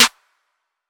Clap1.wav